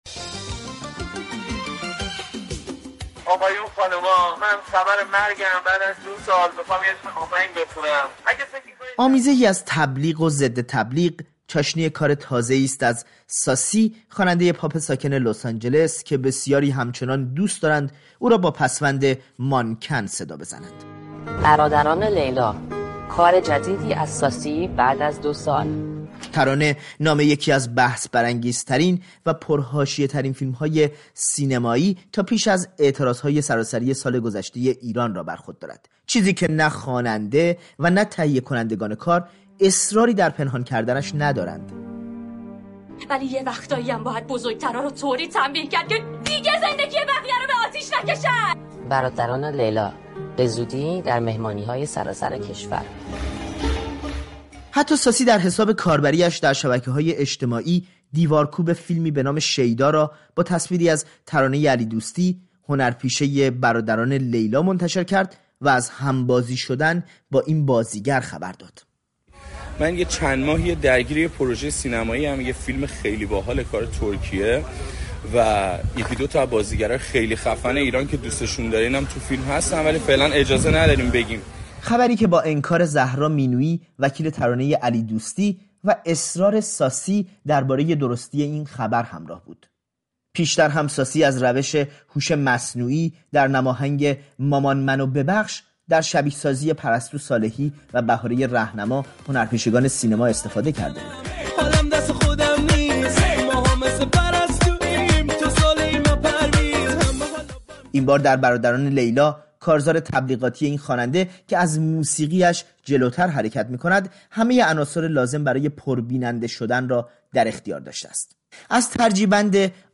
گزارشی در این باره